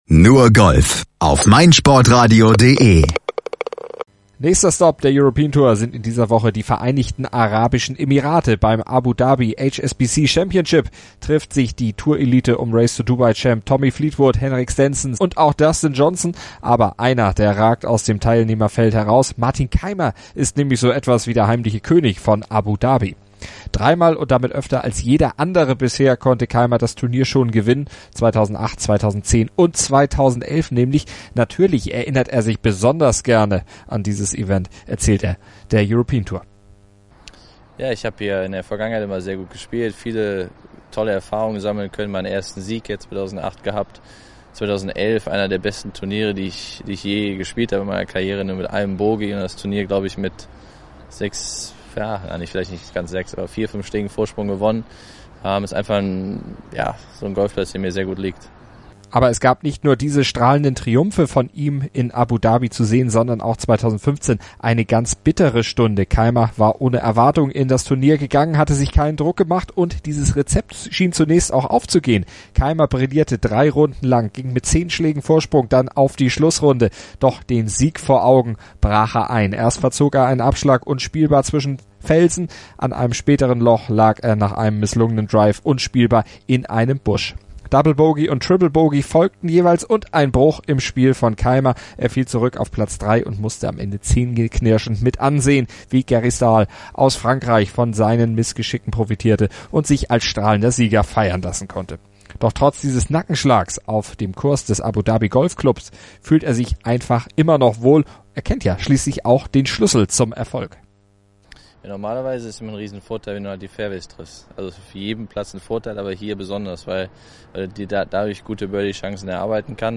lässt Kaymer selbst ebenfalls zu Wort kommen.